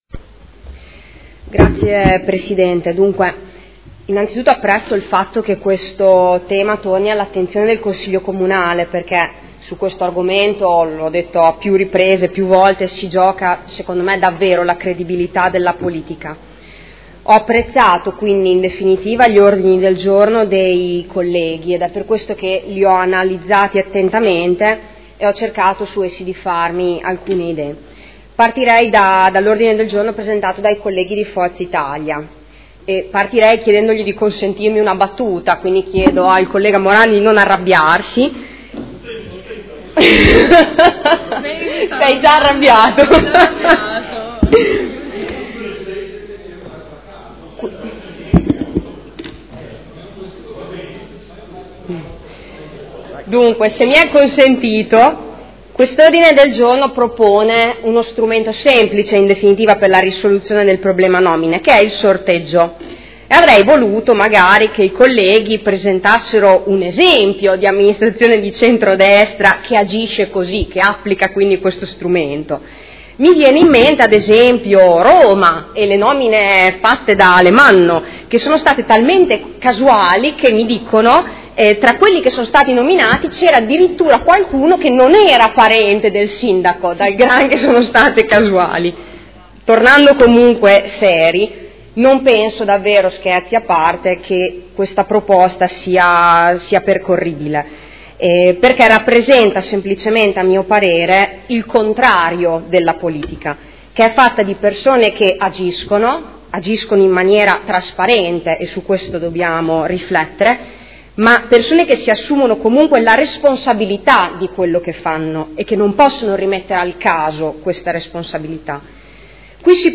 Seduta del 09/12/2015 Dibattito. Ordini del giorno.